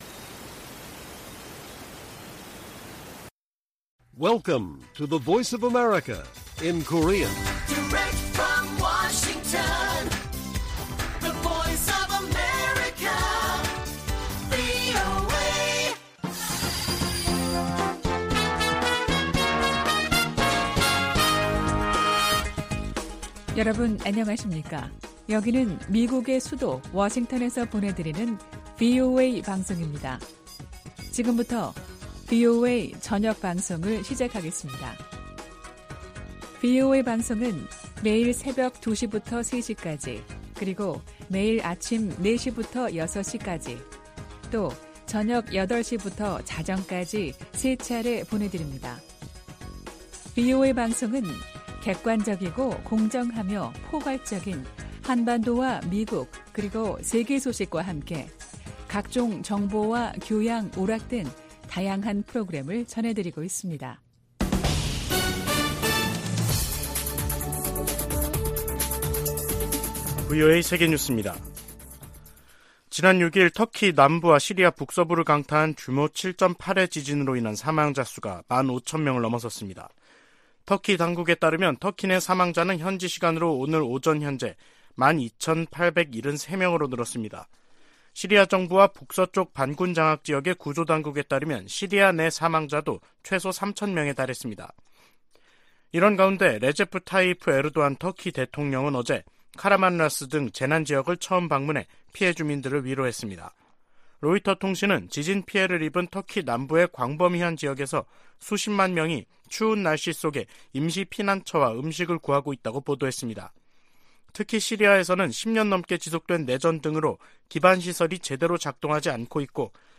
VOA 한국어 간판 뉴스 프로그램 '뉴스 투데이', 2023년 2월 9일 1부 방송입니다. 북한 건군절 기념 열병식에서 고체연료 대륙간탄도 미사일, ICBM으로 추정되는 신형 무기가 등장했습니다. 북한의 핵・미사일 관련 조직으로 추정되는 미사일총국이 공개된 데 대해 미국 정부는 북한 미사일 개발을 억지하겠다는 의지를 확인했습니다.